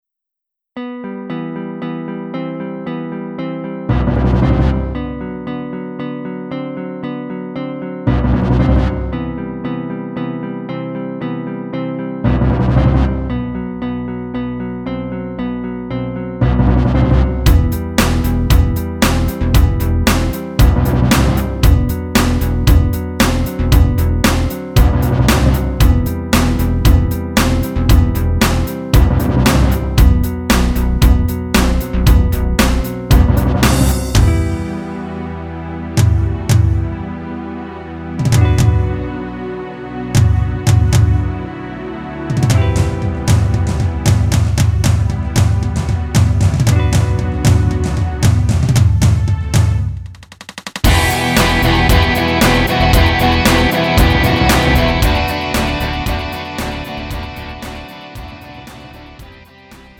음정 -1키
장르 pop 구분 Lite MR